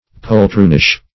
Poltroonish \Pol*troon"ish\